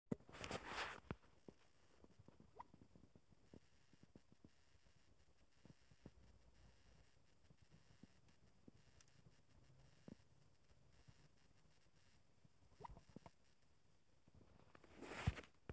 Microsoft Surface Laptop 3: Flup Geräusch
Mir ist heute dieses leise periodische Flup Geräusch aufgefallen.